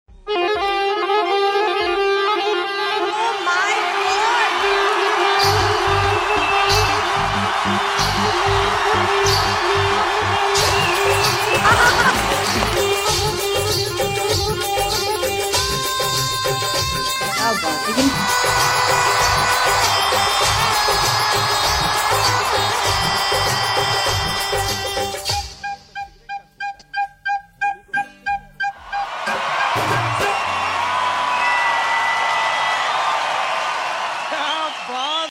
Bollywood Solo hips back belly dance#viralvideo